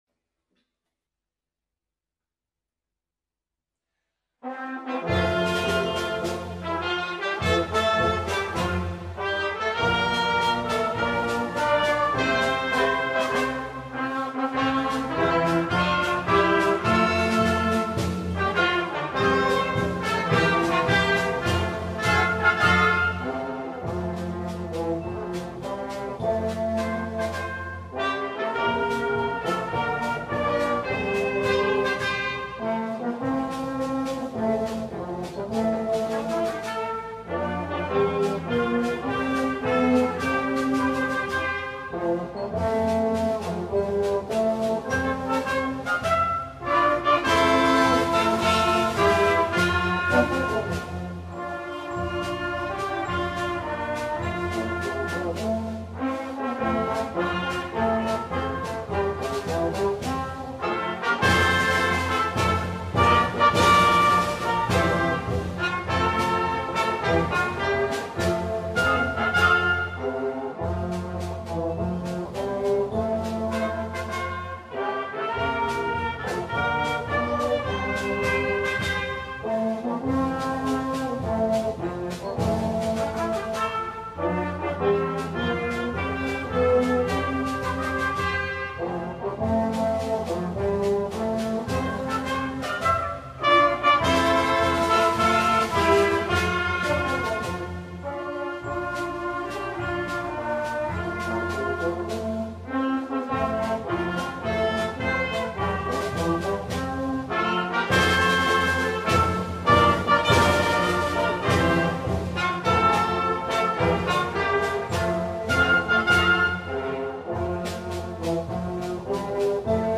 ブラスバンド版
新たな収録は平成25年3月22日くにたち市民芸術小ホールのスタジオで行いました。